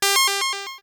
ihob/Assets/Extensions/RetroGamesSoundFX/Alert/Alert07.wav at master
Alert07.wav